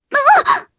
Reina-Vox_Damage_jp_01.wav